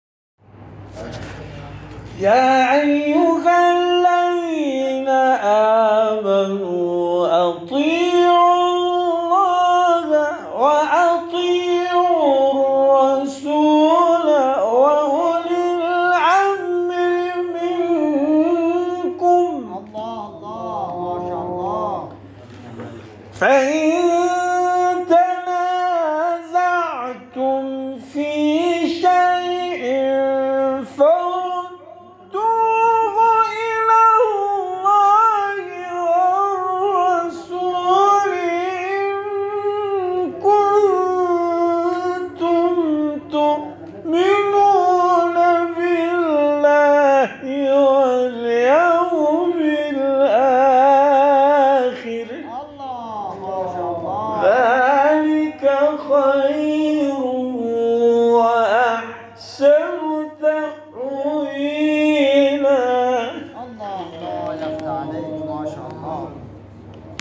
تلاوت نفرات برتر مسابقه از دید داوران:
تلاوت